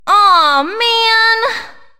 One of Princess Daisy's voice clips in Mario Party 6